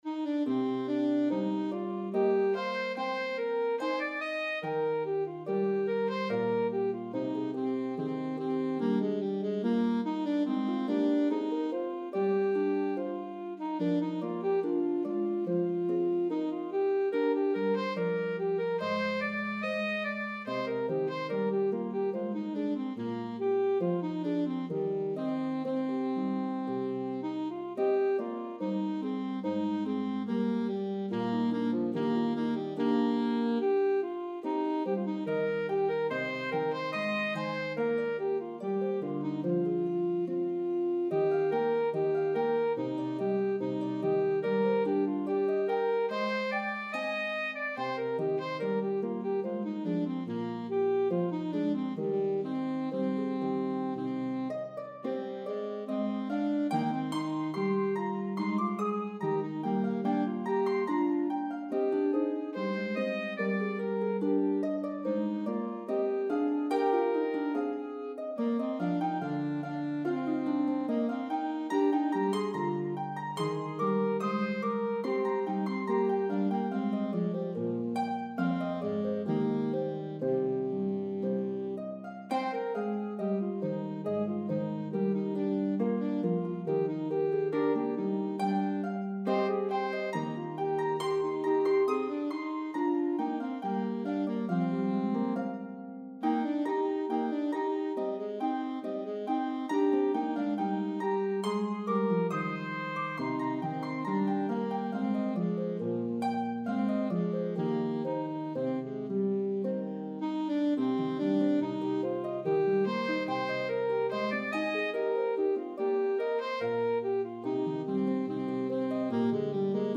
This beautiful Aeolian melody has a debated history.